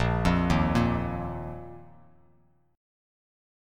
A#sus4 chord